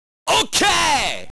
Descarga el sonido "OK!" de Terry en formato WAV